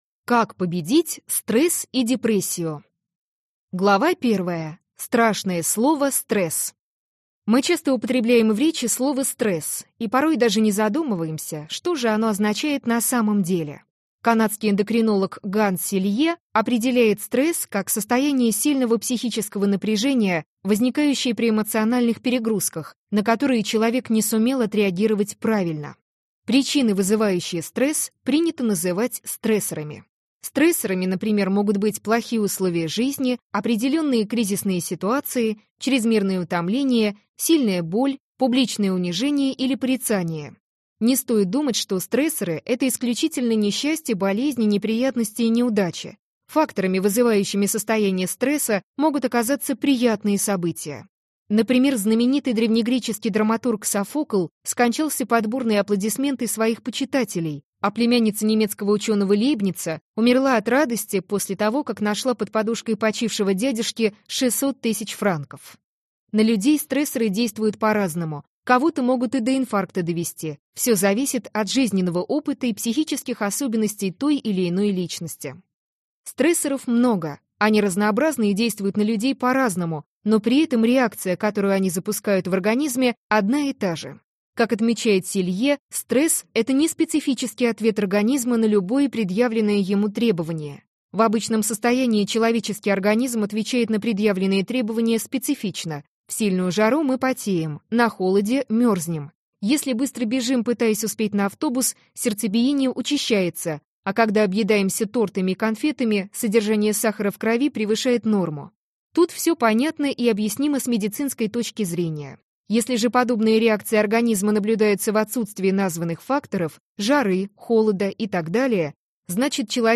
Аудиокнига Как преодолеть стресс и депрессию | Библиотека аудиокниг